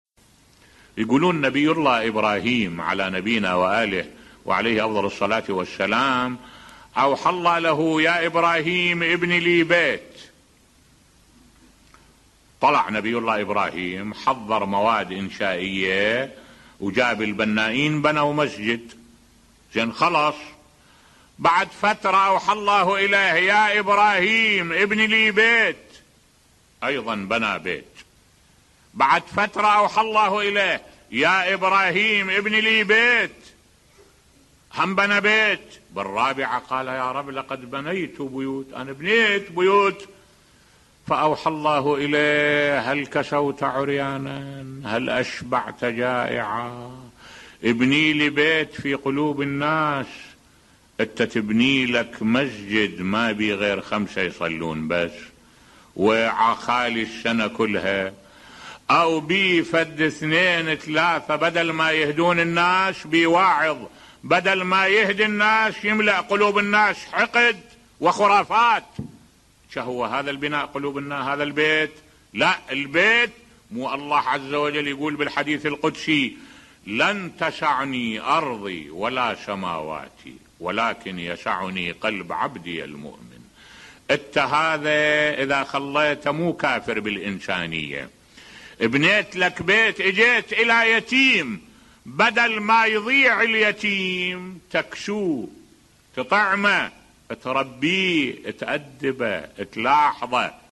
ملف صوتی إبني لنفسك بيتا في قلوب الناس بصوت الشيخ الدكتور أحمد الوائلي